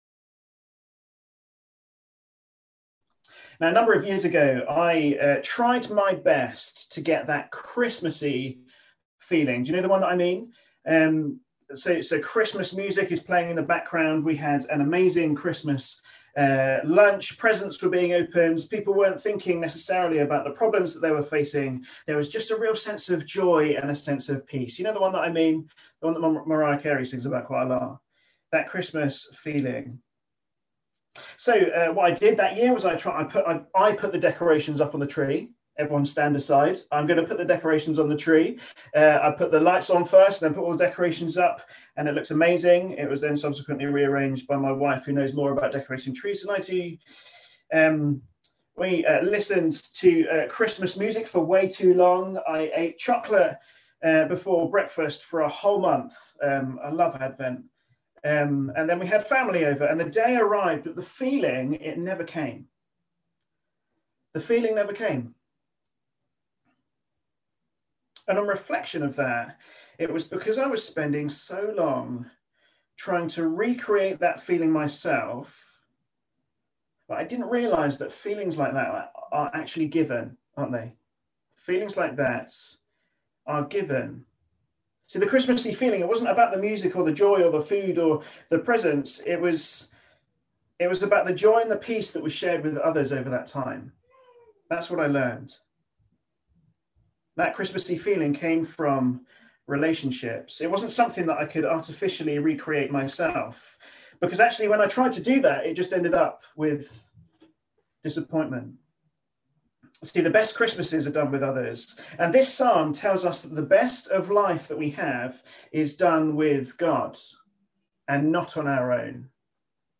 A sermon preached on 27th September, 2020.